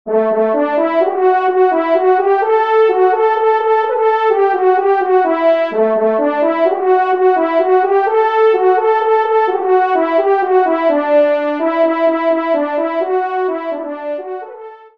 Fanfare de personnalité
Pupitre de Chant